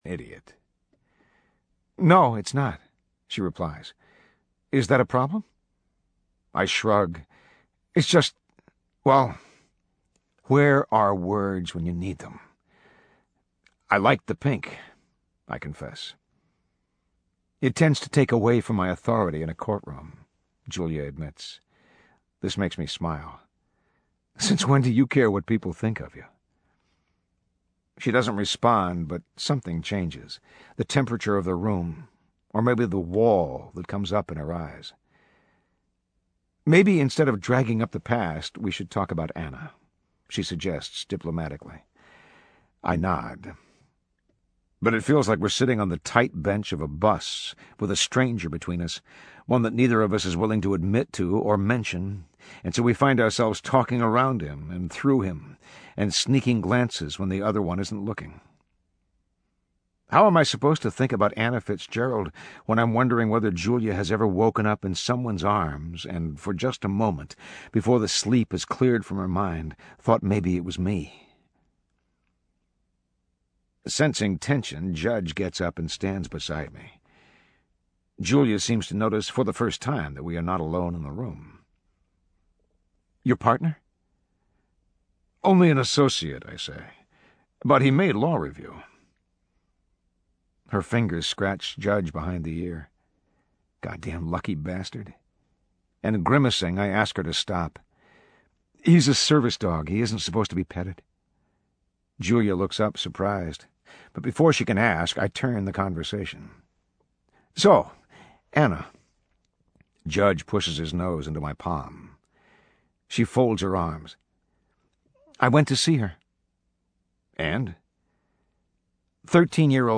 英文广播剧在线听 My Sister's Keeper（姐姐的守护者）49 听力文件下载—在线英语听力室